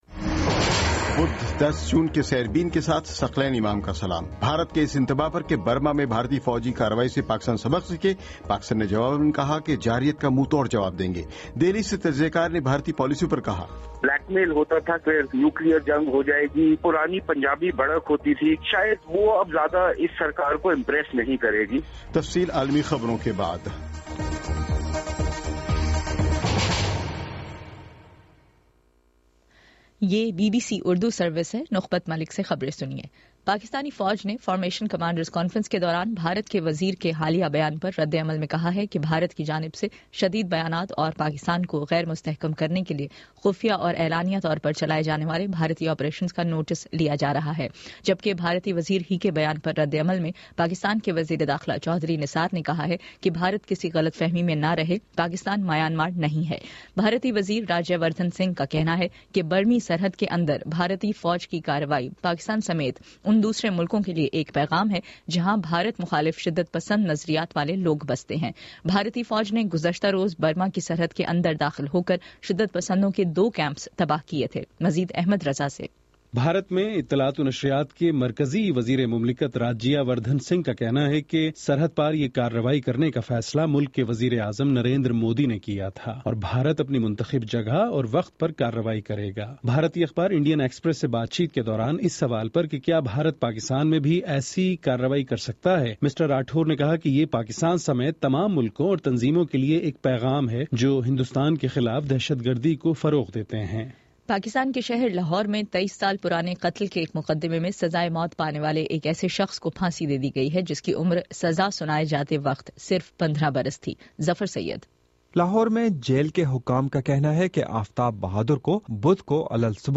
بدھ 10 جون کا سیربین ریڈیو پروگرام